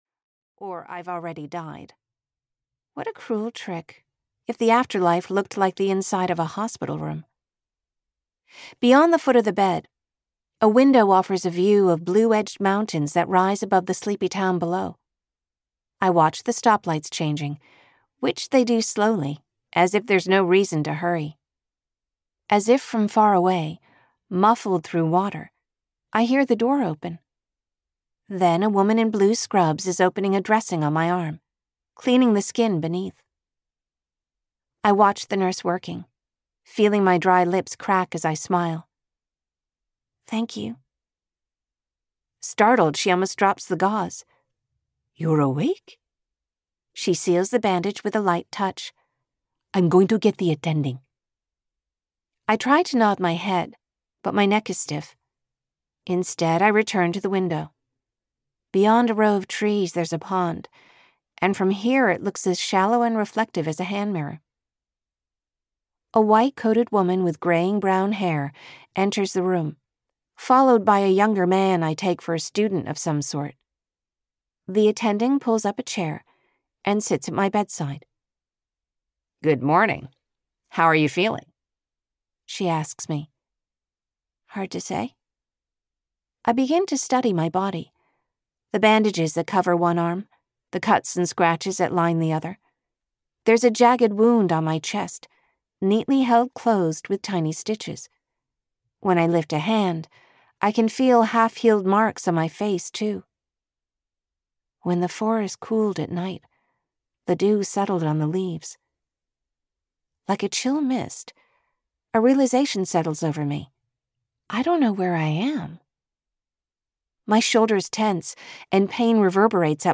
The Silent Girl (EN) audiokniha
Ukázka z knihy